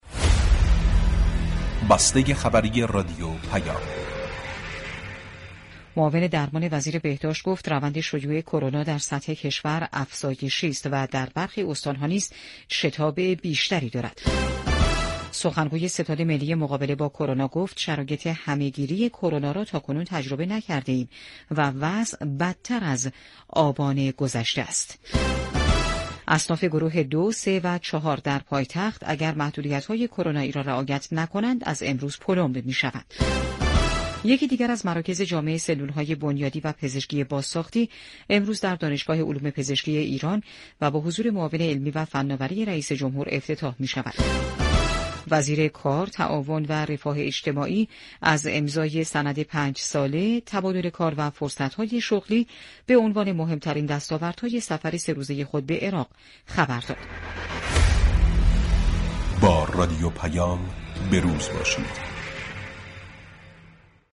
بسته ی خبری رادیو پیام